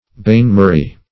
Bain-marie \Bain`-ma`rie"\, n. [F.]